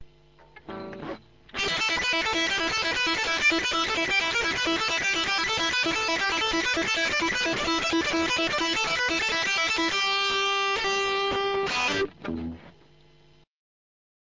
Picking
Verzerrt